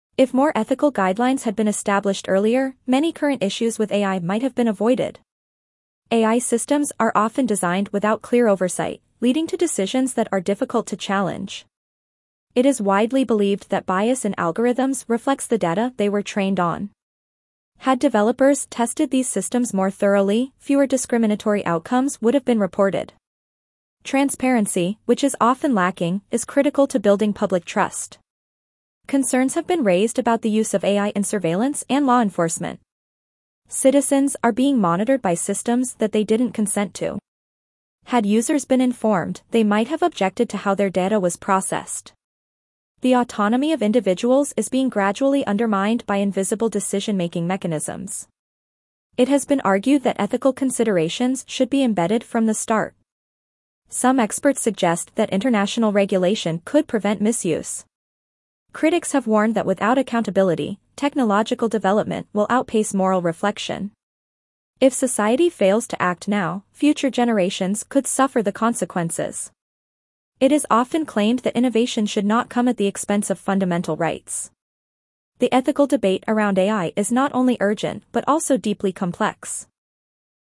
Your teacher will read the passage aloud.
(Teacher’s Script – 15 Sentences):